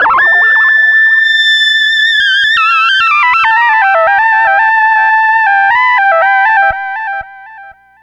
Synth 10.wav